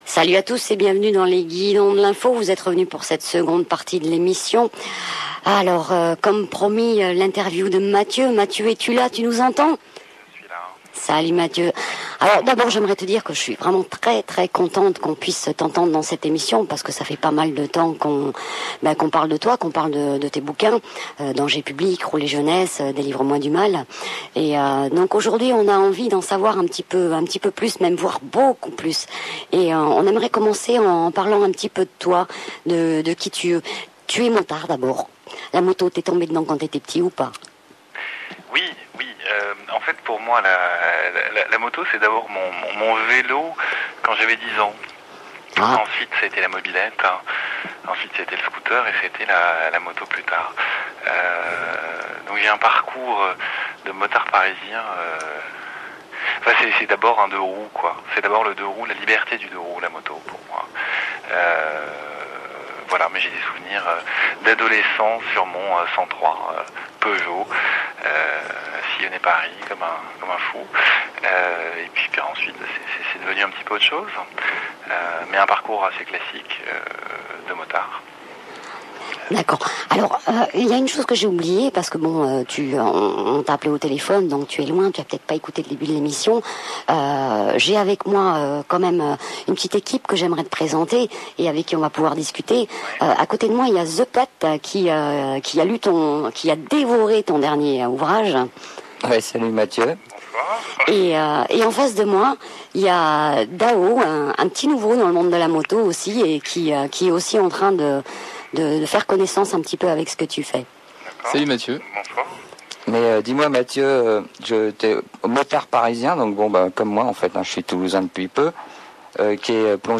Interview
interview.mp3